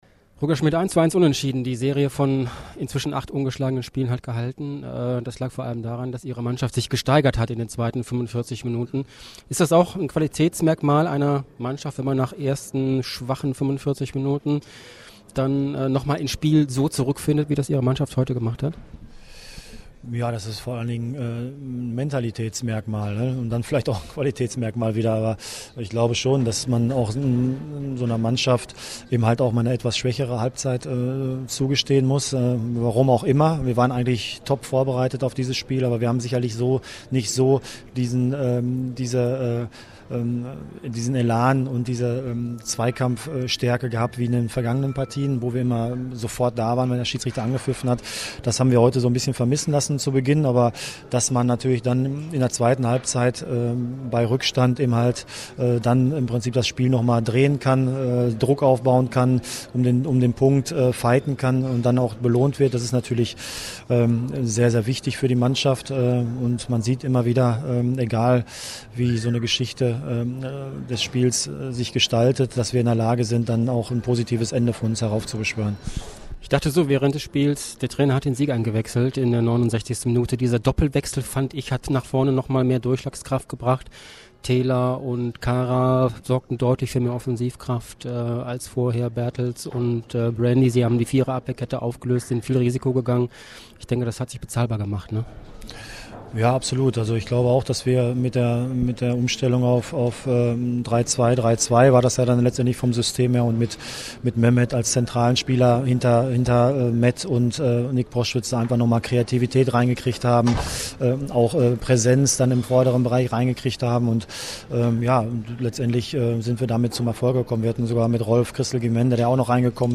Audiokommentar
Chef-Trainer Roger Schmidt zum Spiel